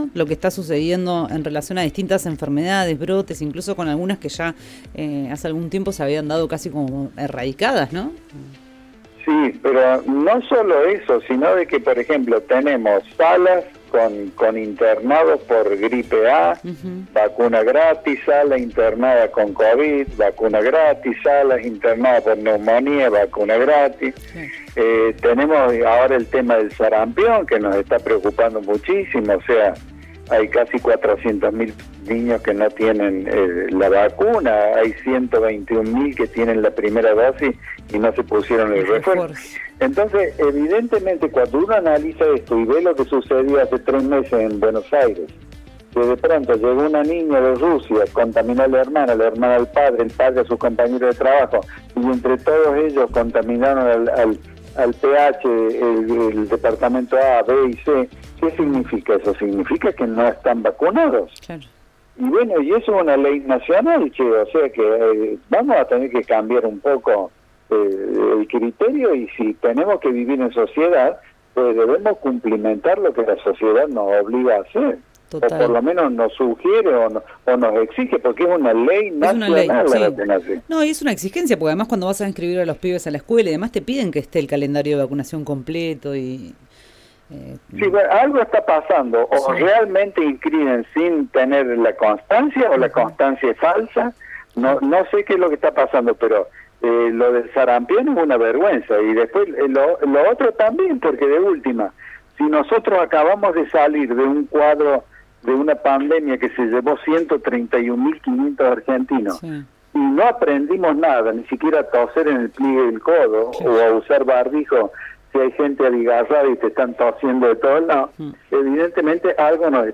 en diálogo con El diario del mediodía por RÍO NEGRO RADIO